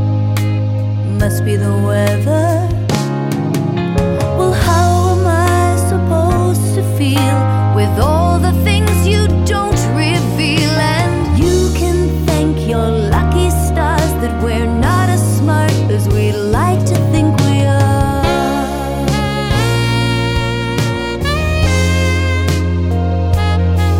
For Solo Male Duets 3:59 Buy £1.50